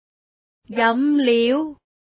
字詞： 飲料(jam2 liu6) drinks 解釋： 經過加工製造供人飲用的液體，如汽水、果汁等 Processed edible liquid for consumption, such as soft drinks, juices, etc. 句子： 夏天，小食店的飲料非常暢銷。